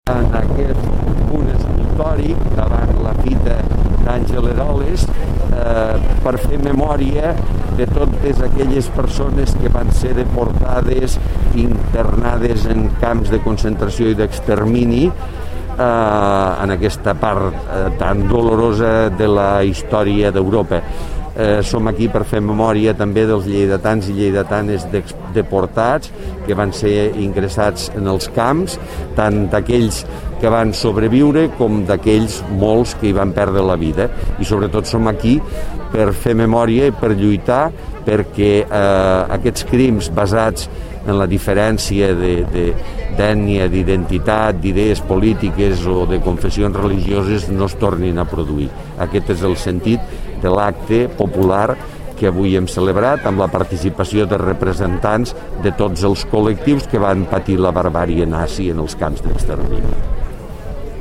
La ciutat de Lleida ha commemorat el Dia Internacional en memòria de les víctimes de l’Holocaust amb un acte, que s’ha desenvolupat aquest matí, entorn l’escultura Fita, d’Àngel Eroles, i en el qual han intervingut els principals col·lectius que van patir la persecució per part del nazisme. L’alcalde de Lleida, Miquel Pueyo, s’ha dirigit als assistents, agafant com a punt de partida del seu missatge el lema de la diada: ‘Sigues la llum en la foscor’.
tall-de-veu-miquel-pueyo